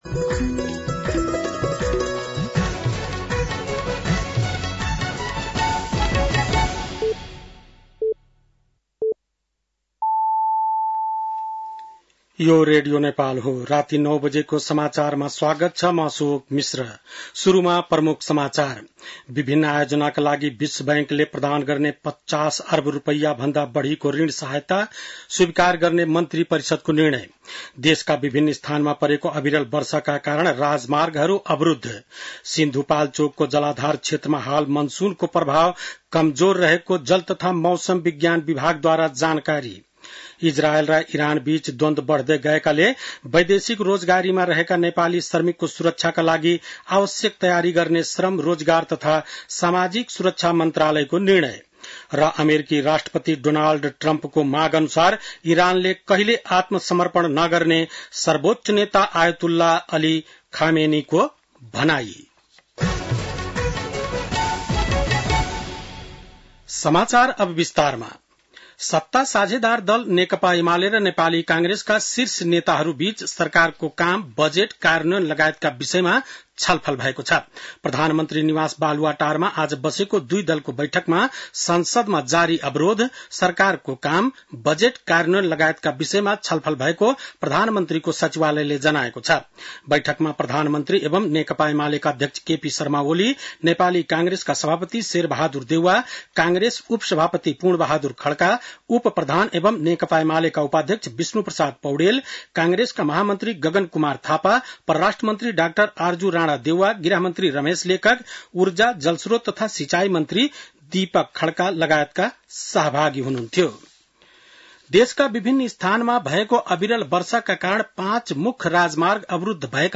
बेलुकी ९ बजेको नेपाली समाचार : ४ असार , २०८२
9-pm-nepali-news-3-04.mp3